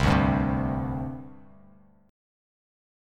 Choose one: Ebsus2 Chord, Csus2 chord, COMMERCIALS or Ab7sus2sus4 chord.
Csus2 chord